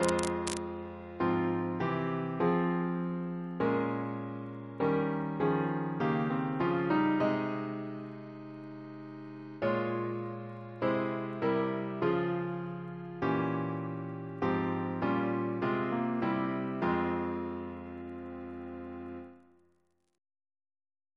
Double chant in F minor Composer: Chris Biemesderfer (b.1958)